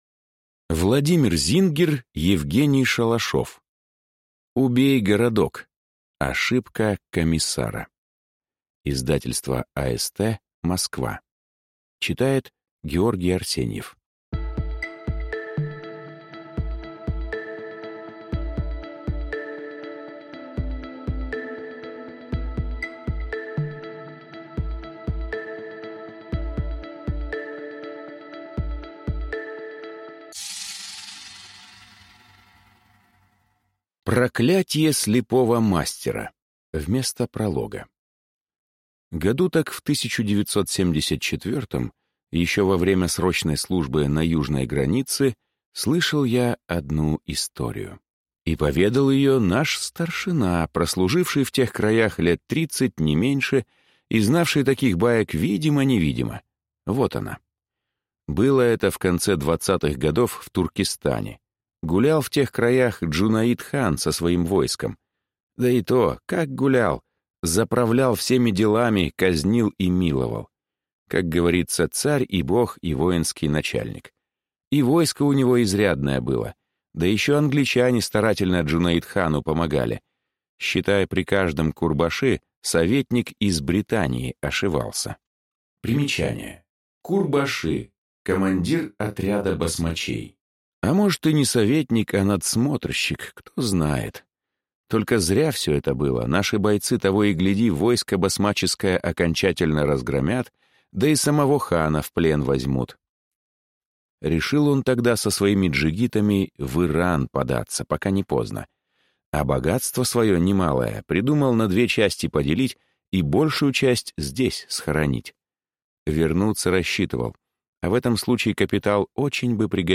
Небесная Искра 2. Турнир четырех (слушать аудиокнигу бесплатно) - автор Оливер Ло